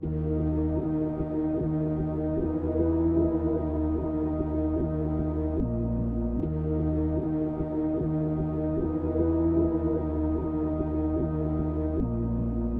声音 " 激进的苏格兰人邀请经理
我知道我的苏格兰口音不是100％完美。
特定的角色是一个激进的苏格兰邀请经理。
使用Cool Edit Pro 2和sE X1录制和编辑。
标签： 名称 侵略性 检查 苏格兰 男性 经理 声乐 控制器 列表 邀请 语音 文字 邀请 人力 语音
声道立体声